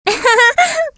Amy rose giggle 1
Etiquetas: meme, soundboard
amy-rose-giggle-1.mp3